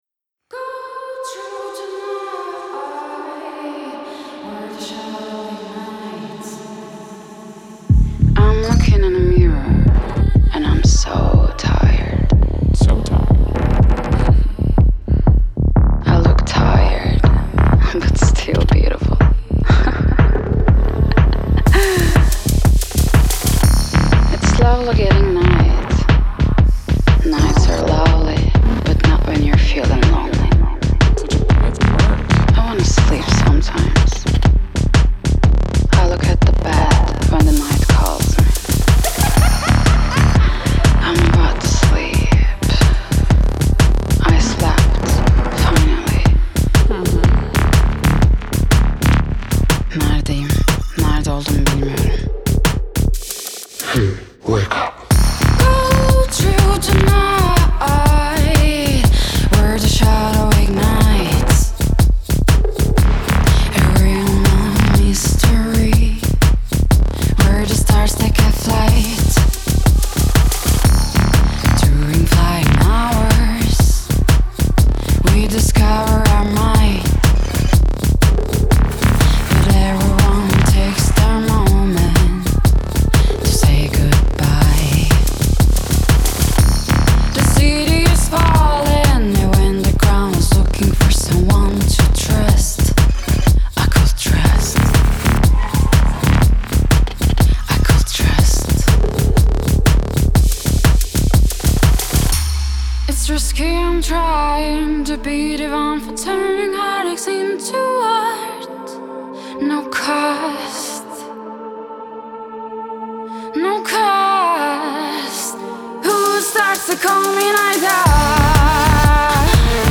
18 бер Жанр: HOUSE Share